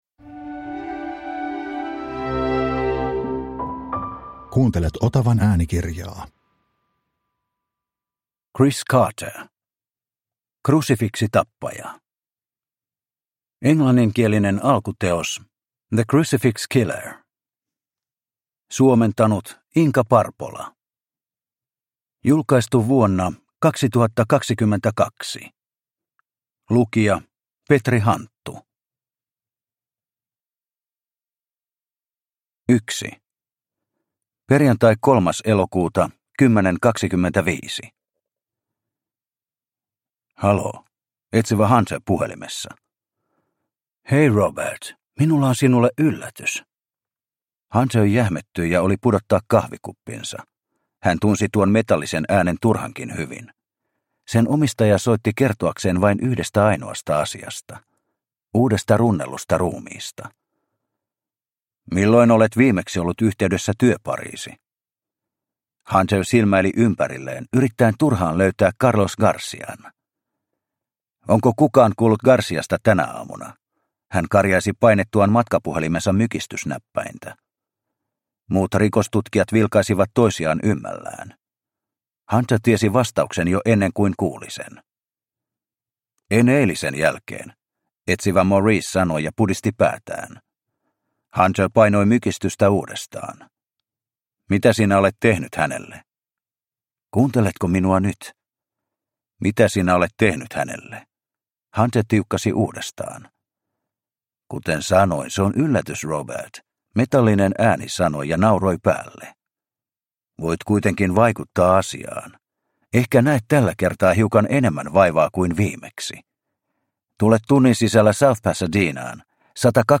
Krusifiksitappaja – Ljudbok – Laddas ner